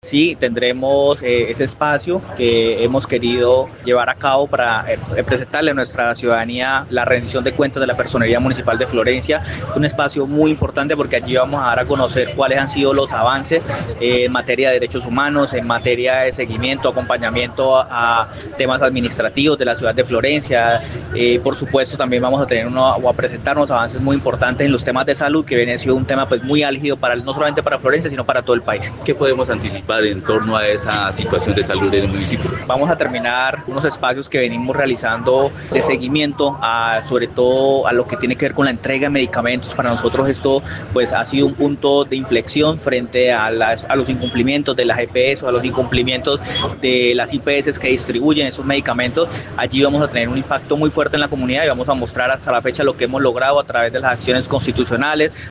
Jorge Luis Lara Andrade, Personero del municipio de Florencia, explicó que el próximo 4 de abril, se conocerán detalles en ese sentido, mismos que se han realizado junto a la Defensoría del Pueblo y la Contraloría Departamental con quienes adelantan visitas a las entidades prestadoras de salud.